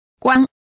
Complete with pronunciation of the translation of how.